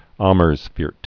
mərz-fœrt, -fôrt, ämərs-)